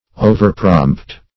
Search Result for " overprompt" : The Collaborative International Dictionary of English v.0.48: Overprompt \O"ver*prompt"\, a. Too prompt; too ready or eager; precipitate.